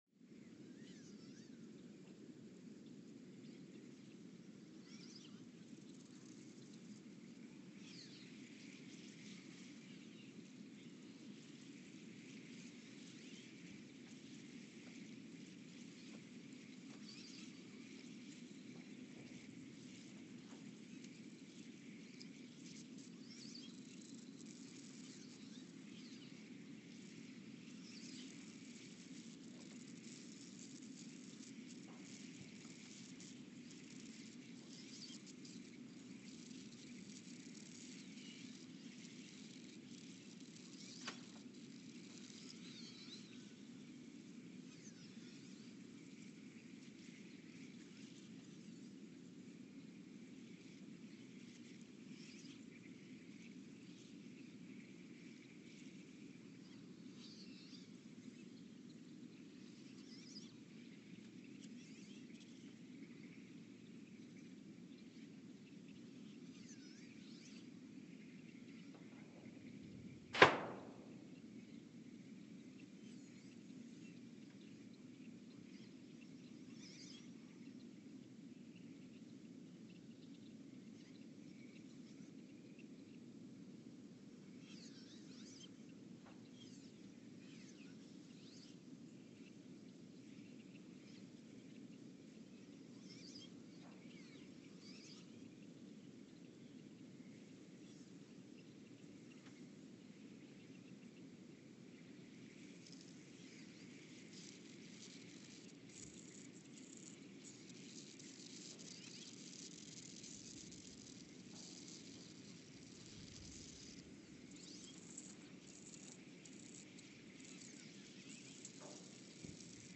Ulaanbaatar, Mongolia (seismic) archived on October 28, 2023
Sensor : STS-1V/VBB
Speedup : ×900 (transposed up about 10 octaves)
Loop duration (audio) : 03:12 (stereo)
Gain correction : 25dB
SoX post-processing : highpass -2 90 highpass -2 90